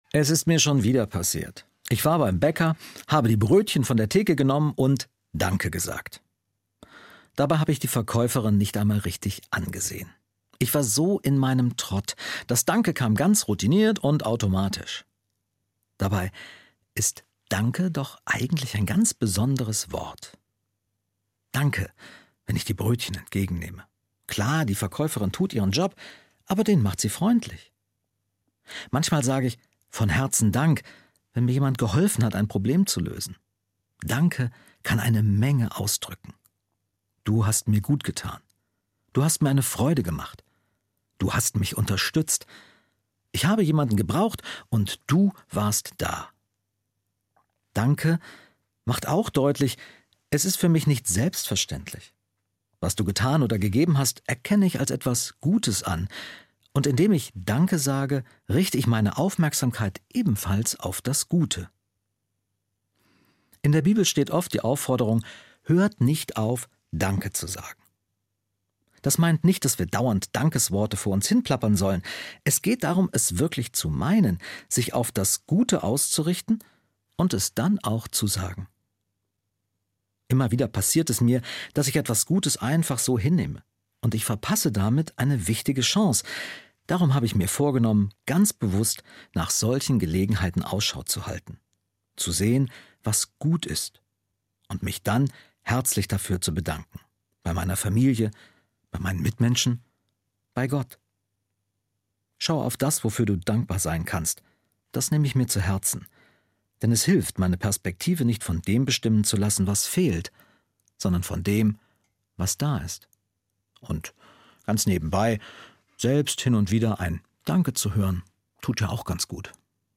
Evangelischer Pastor, Marburg